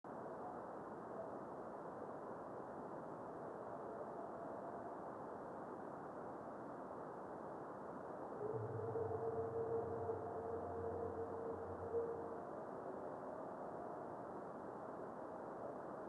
dual forward scatter system;   video and stereo sound:
Short reflection.
Meteor reflection occurs during the 0658 UT minutes.